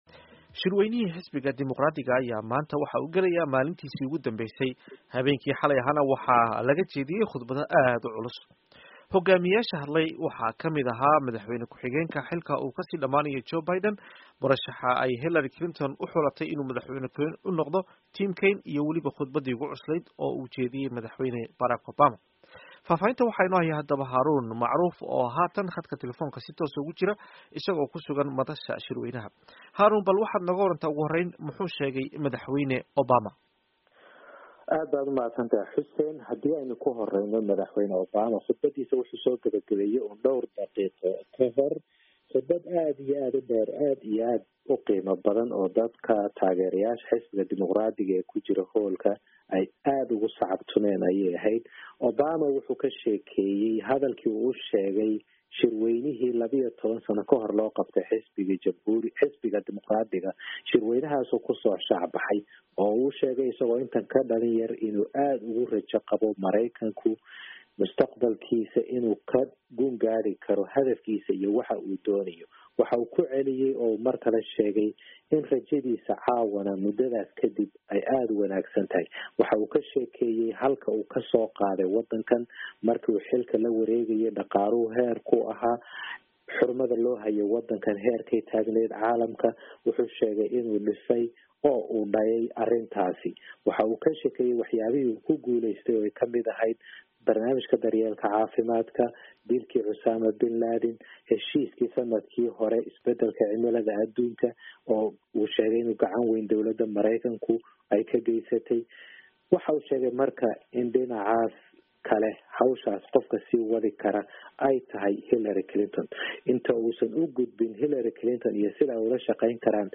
Dhageyso Wareysiga Shirka Dimuqraaddiga